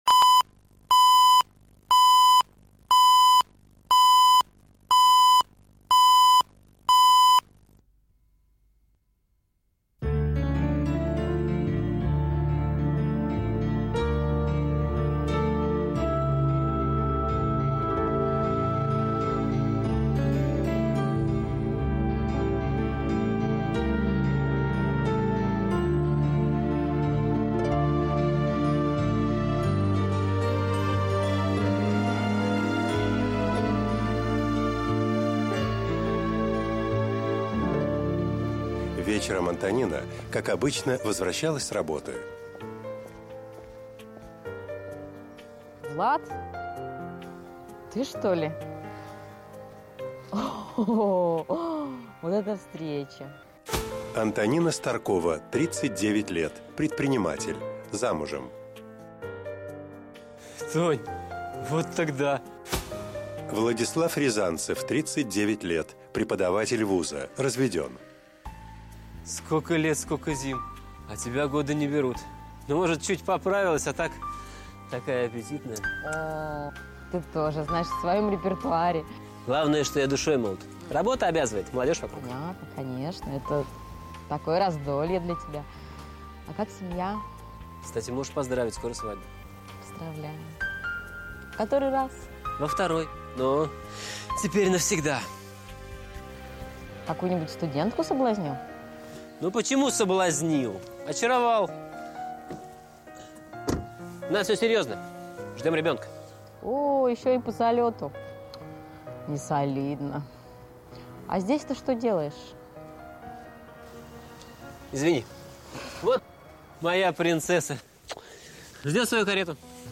Аудиокнига Кто отец?